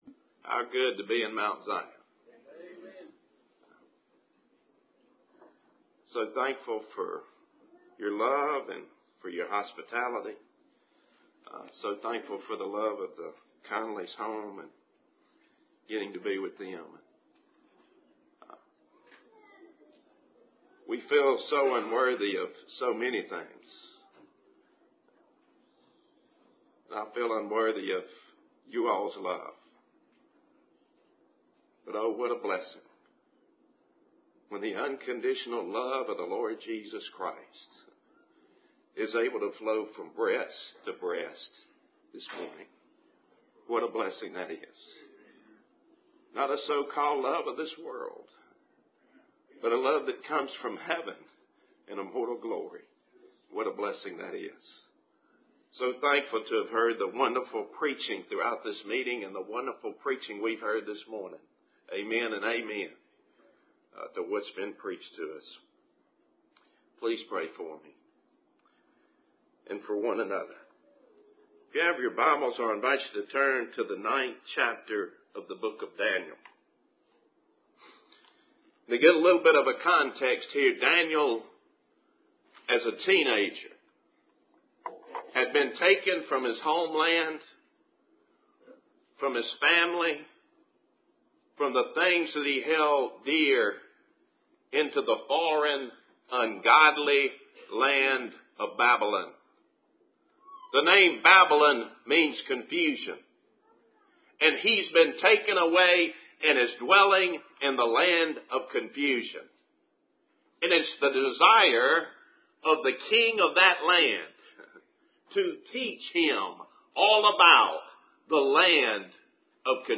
Service Type: Ebenezer Fellowship Meeting